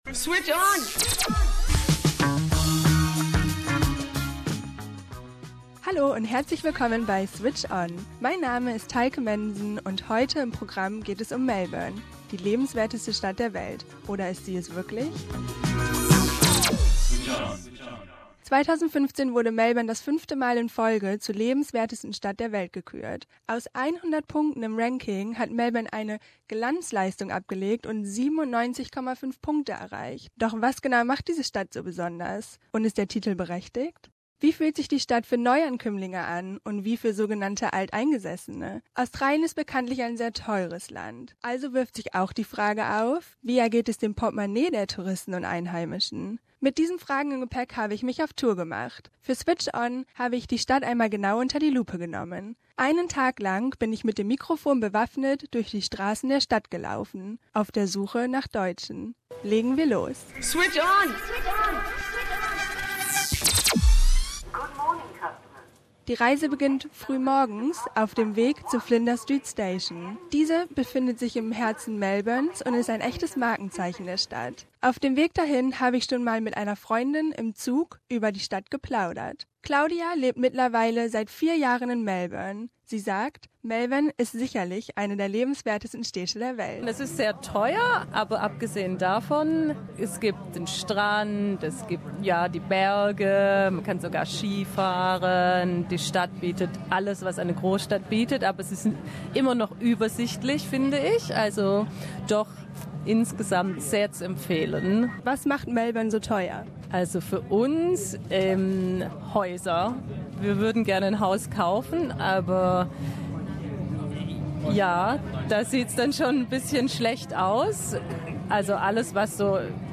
Wir unterhalten uns mit Leuten auf der Straße und schauen mal ob es wirklich so lebenswert in Melbourne ist.